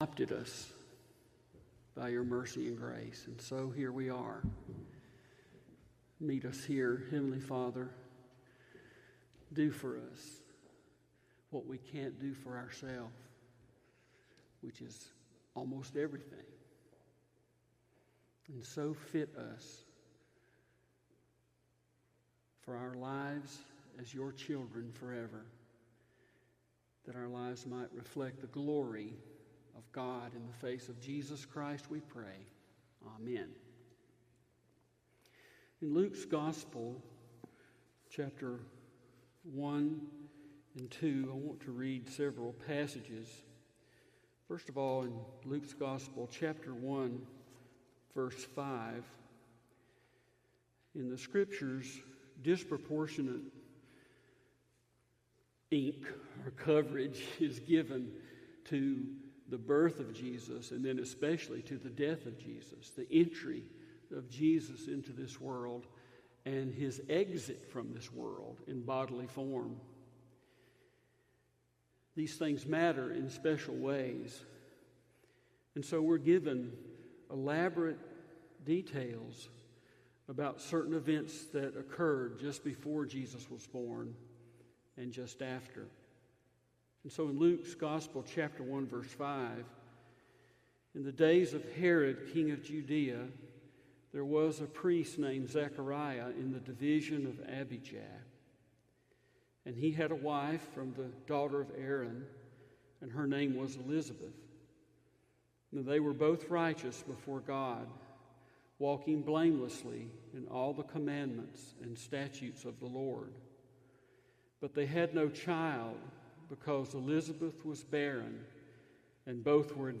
Sermons | Smoke Rise Baptist Church
Luke 2 / Christmas Message